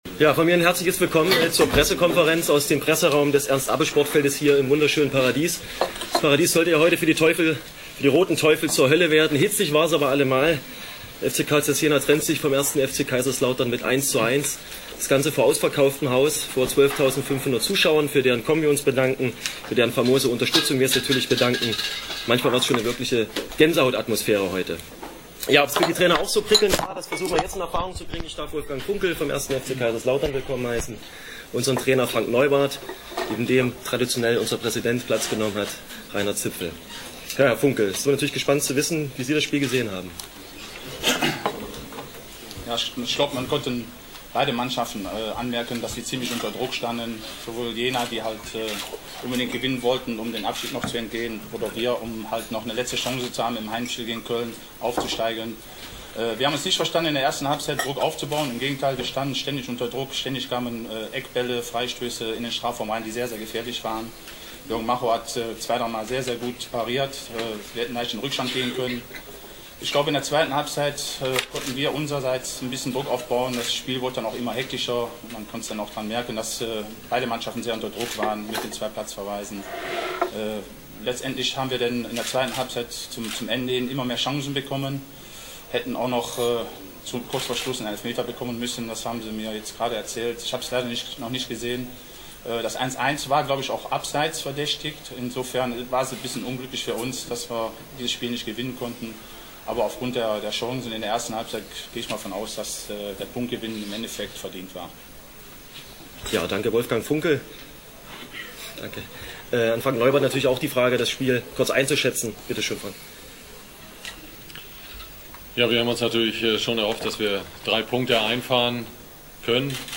Download) Pressekonferenz nach dem Spiel: